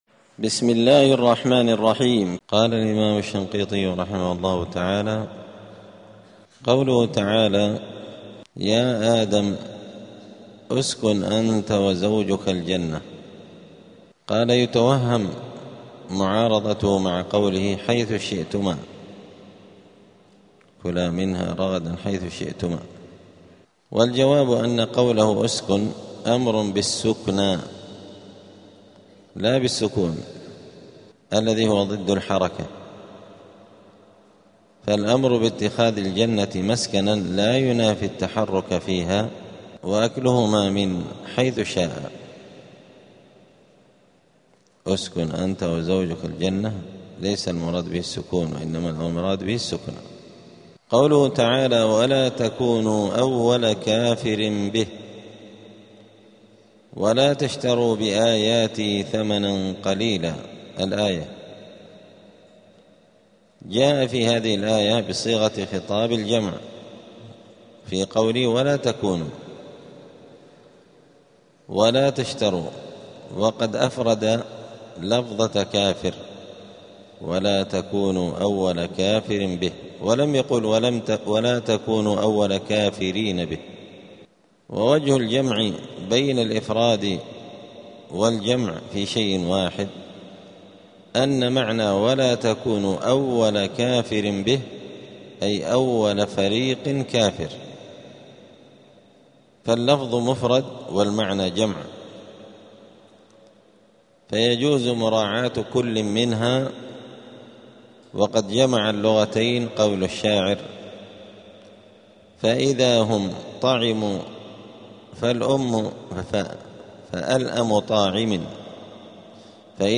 *الدرس السابع (7) {سورة البقرة}.*
دار الحديث السلفية بمسجد الفرقان قشن المهرة اليمن